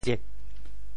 唧 部首拼音 部首 口 总笔划 10 部外笔划 7 普通话 jī 潮州发音 潮州 ziêg4 文 中文解释 唧 <象> (形声。
tsiek4.mp3